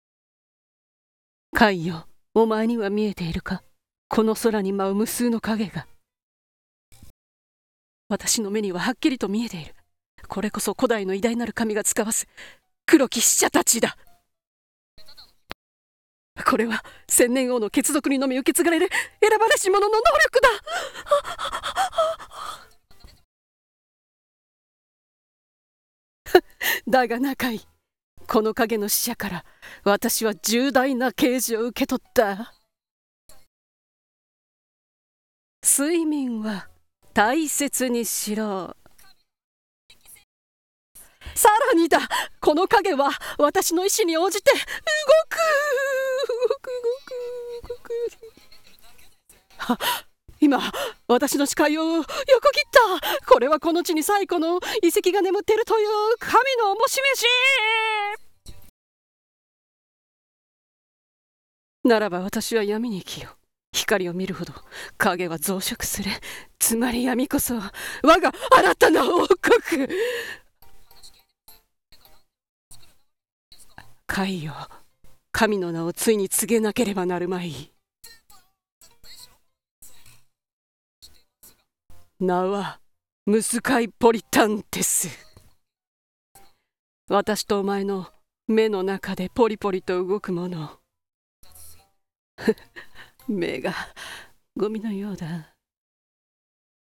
【ギャグ】ムスカイポリタンテス【2人声劇】